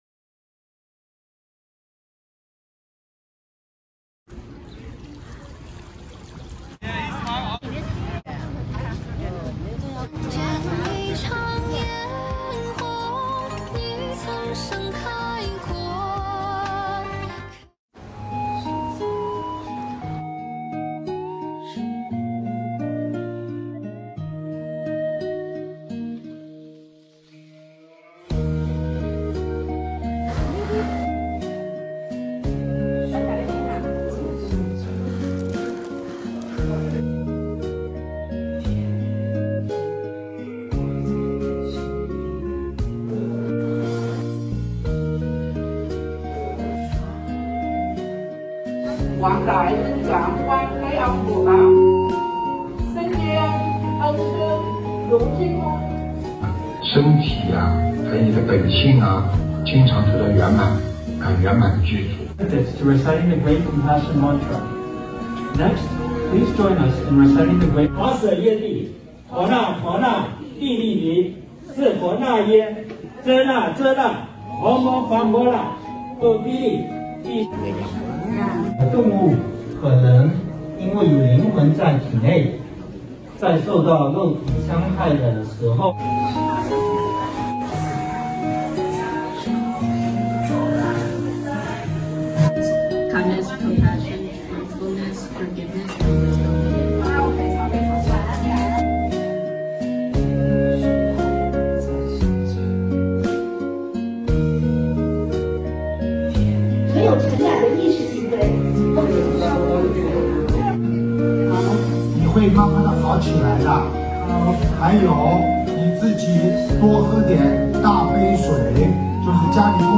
音频：沙加缅度素餐视频会！2023年01月01日